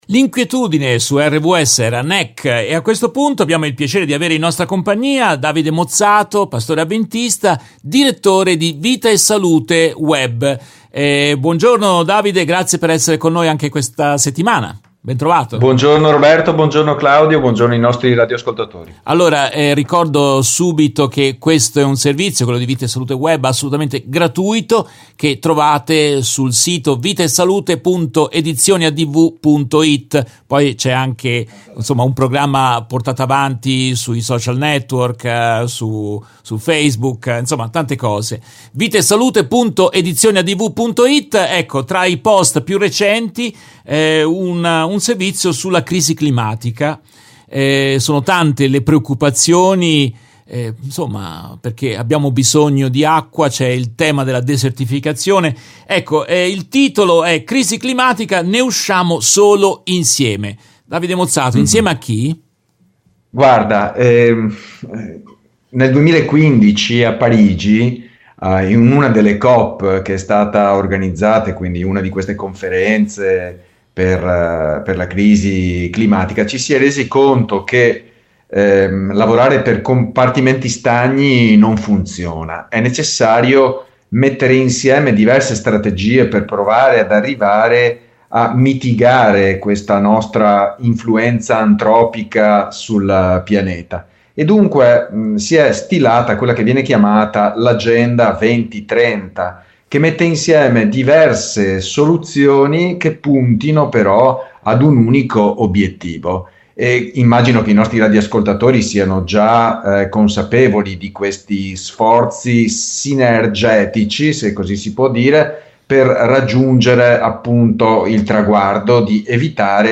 intervistano